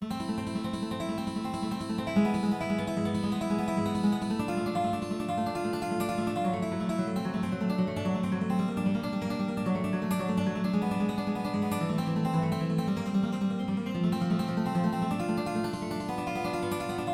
快速琶音指法吉他
描述：4小节的快速，基于三连音的民谣吉他指法琶音。